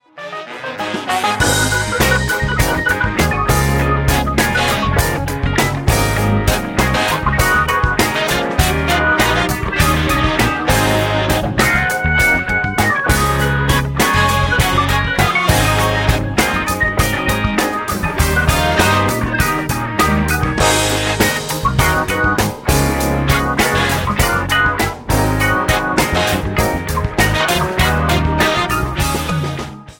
JAZZ  (03.57)